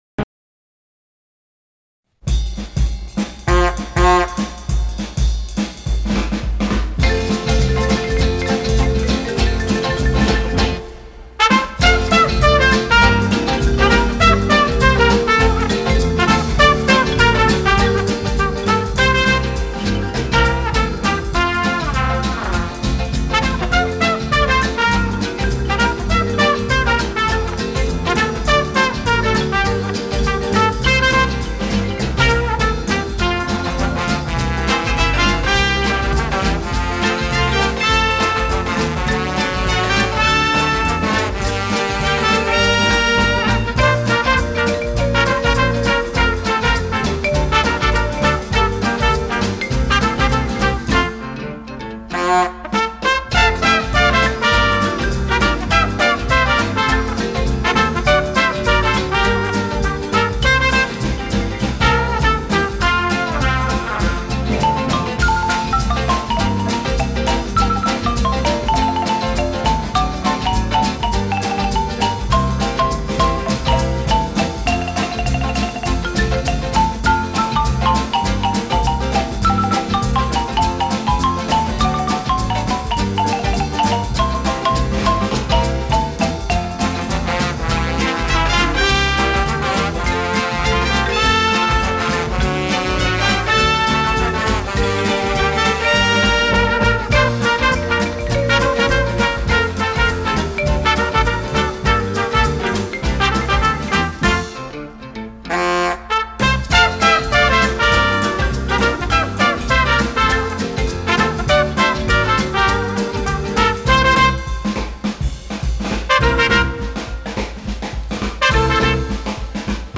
Eguerdiko Ahotsa magazine, informatibo, giza-kultural bat da, eta bertan prentsa errepasoa, elkarrizketak, kaleko iritziak, kolaboratzaileen kontakizunak, musika, agenda eta abar topatu ditzazkezu.